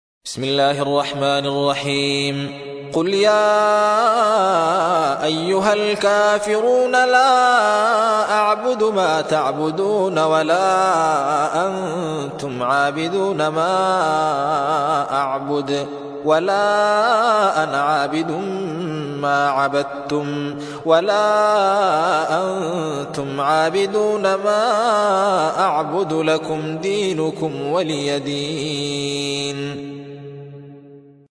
109. سورة الكافرون / القارئ